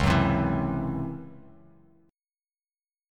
C#mM7bb5 chord